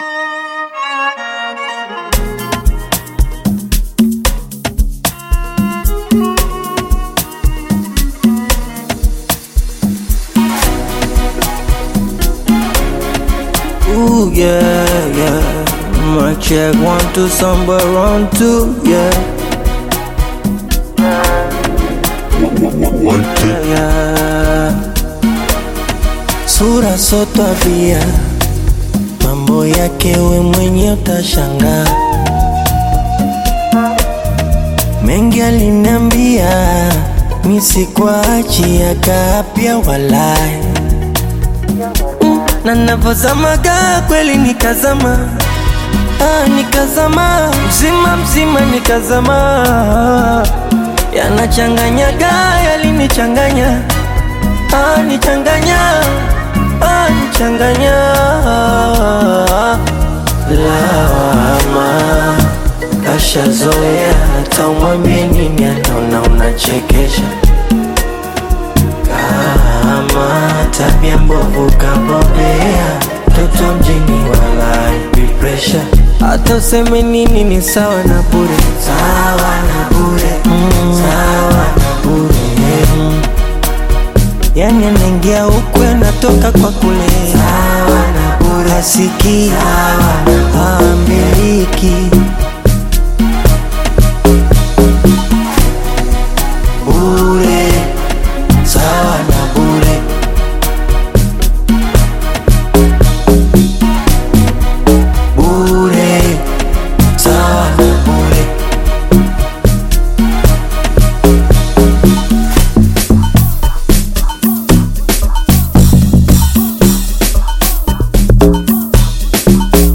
Bongo Flava music track
Bongo Flava song